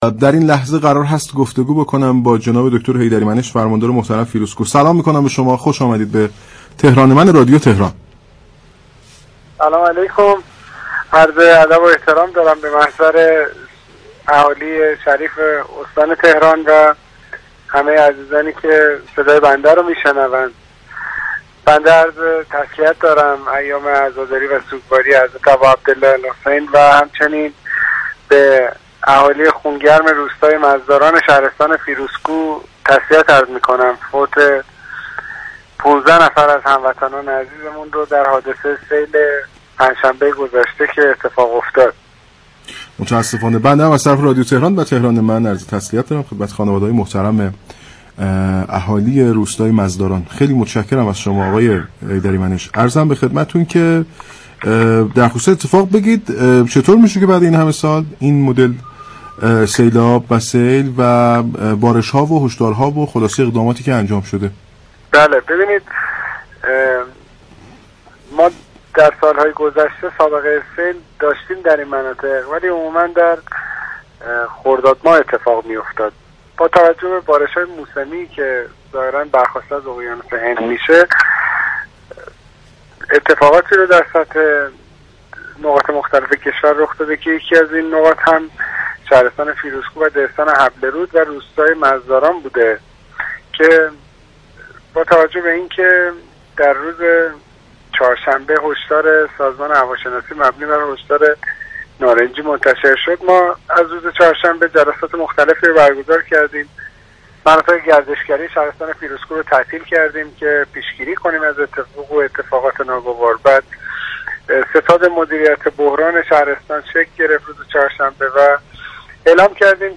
به گزارش پایگاه اطلاع رسانی رادیو تهران، محمد حیدری منش فرماندار فیروزكوه در گفت‌وگو با تهران من با اشاره به خسارات ناشی از سیلاب و بارش شدید باران در شهرستان فیروزكوه گفت: سیلاب فیروزكوه 15 كشته و تعدادی مصدوم داشته است.